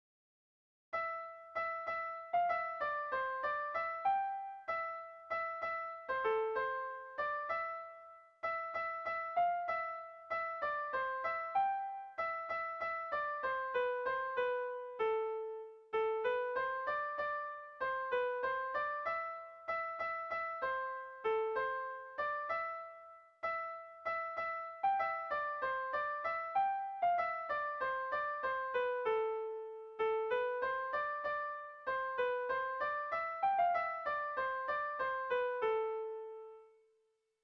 Sentimenduzkoa
Hamarreko handia (hg) / Bost puntuko handia (ip)
A1A2B1AB2